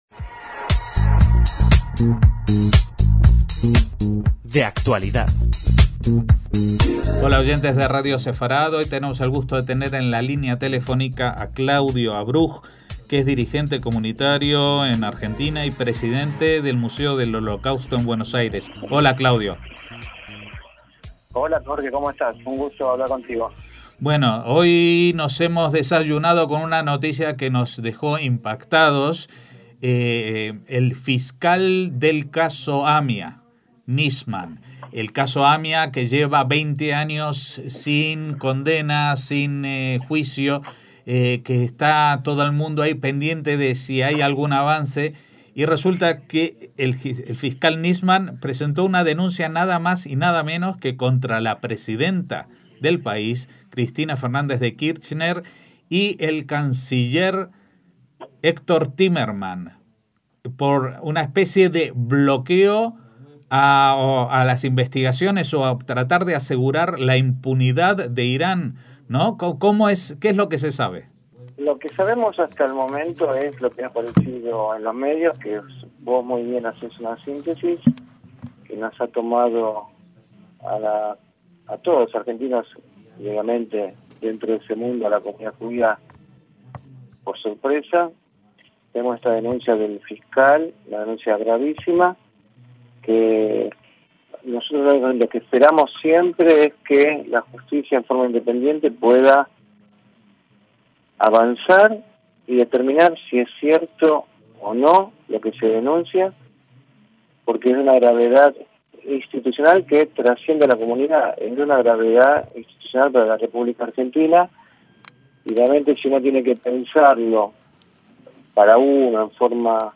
DE ACTUALIDAD - Hablamos con Claudio Avruj, dirigente comunitario y actualmente Presidente del Museo del Holocausto de Buenos Aires, sobre una insólita noticia que podría sacudir los cimientos del gobierno argentino, según la cual Alberto Nisman, fiscal en el caso del atentado contra la mutual judía AMIA en 1994 que causó 85 muertes, denuncia a la mismísima presidente Cristina Fernández de Kirchner y al canciller Héctor Timerman (además de otros dirigentes afines al gobierno) por encubrimiento y alianza con el terrorismo.